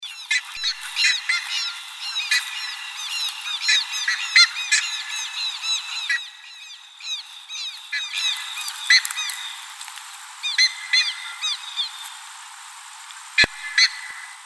Bandurria Boreal (Theristicus caudatus)
Vocalización en vuelo de una bandada de varias
Localidad o área protegida: Reserva Natural Formosa
Condición: Silvestre
Bandurria-Boreal.MP3